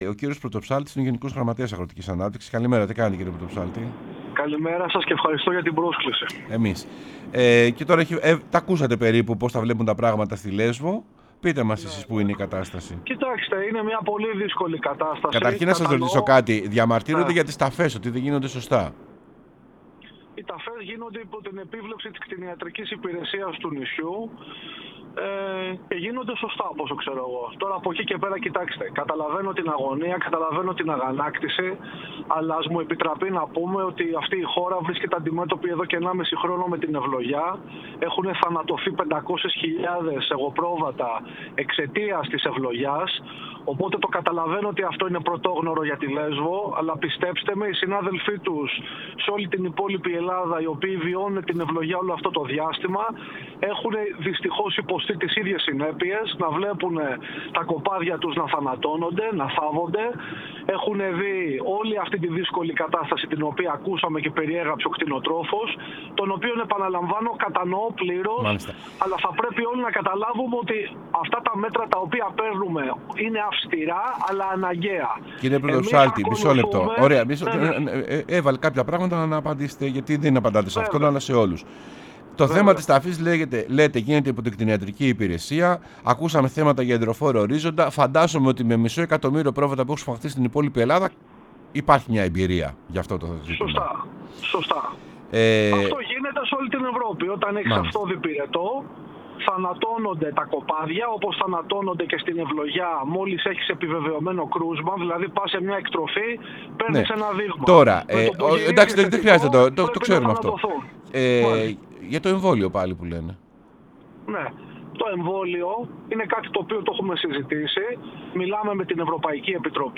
Ο Σπύρος Πρωτοψάλτης, Γενικός Γραμματέας Αγροτικής Ανάπτυξης, μίλησε στην εκπομπή «Σεμνά και Ταπεινά»